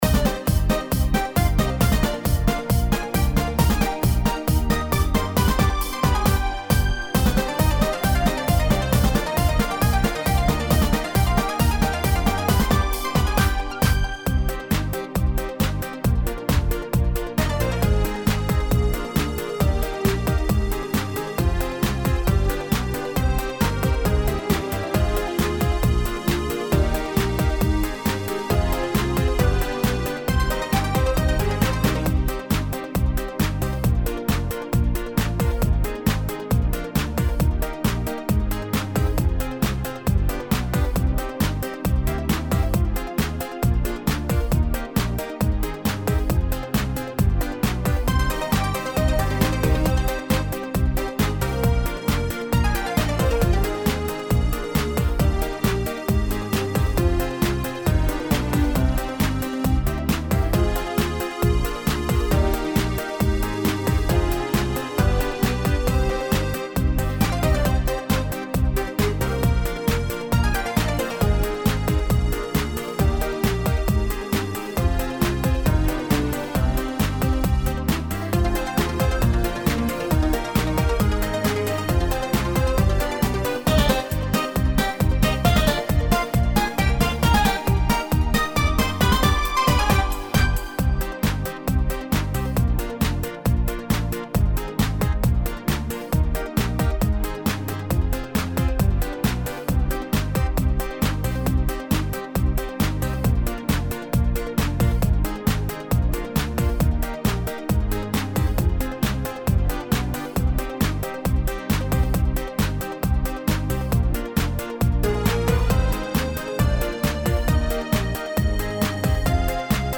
Фонограма-мінус (mp3, 256 kbps).